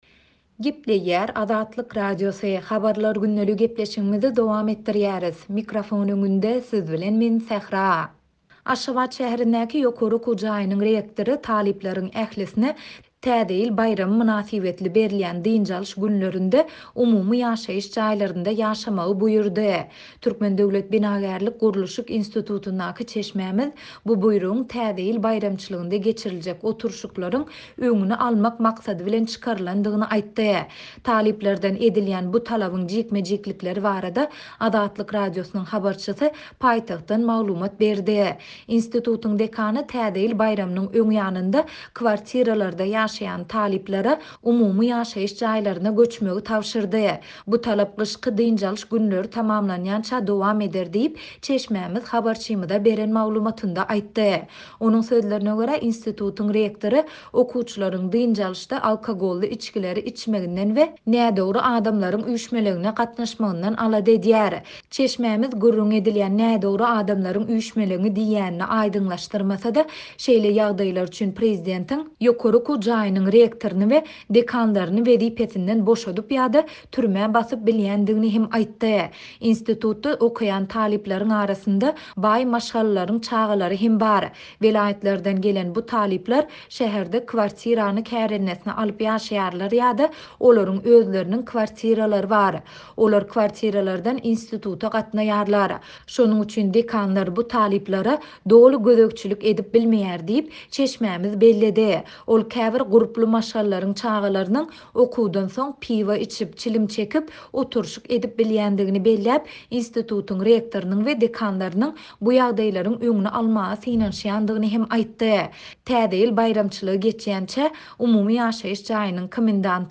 Talyplardan edilýän bu talabyň jikme-jiklikleri barada Azatlyk Radiosynyň habarçysy paýtagtdan maglumat berýär.